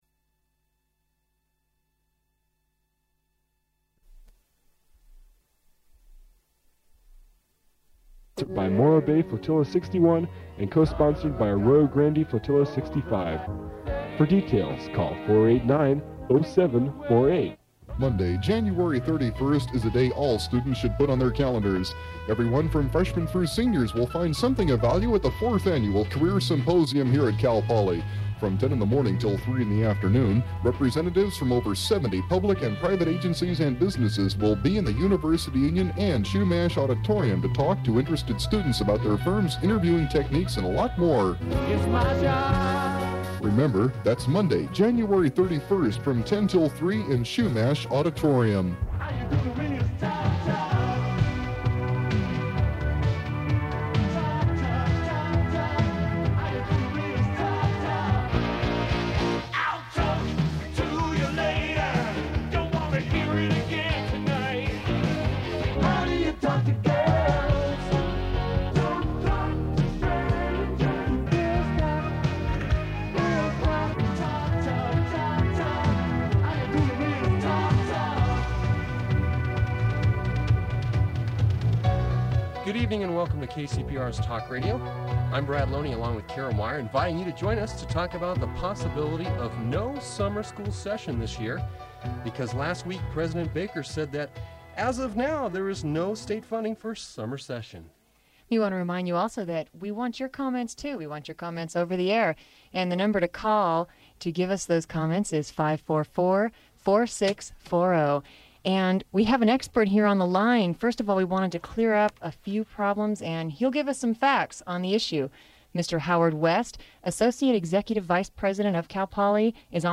The recording abruptly ends at the 48-minute mark, and then resumes at [00:53:43] with a short recording of reversed sped-up vocals.
Form of original Open reel audiotape